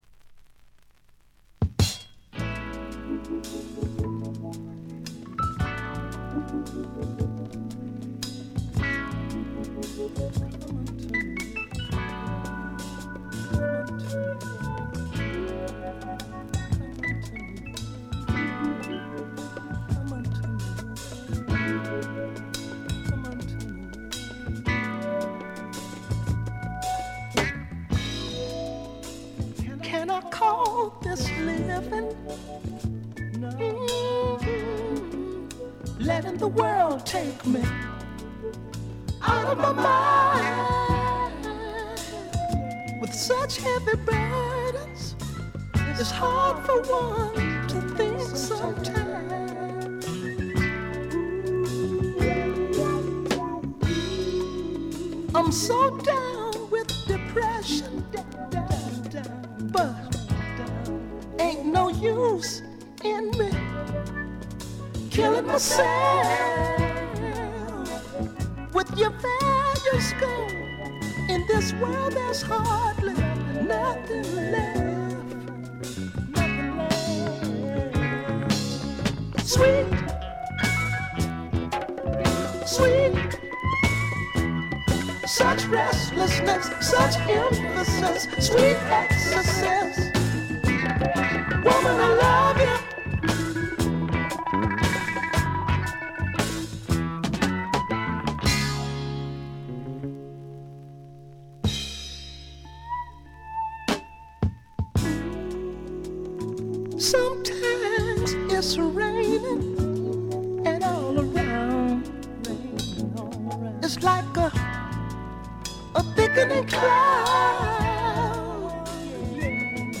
静音部での微細なバックグラウンドノイズ程度。
試聴曲は現品からの取り込み音源です。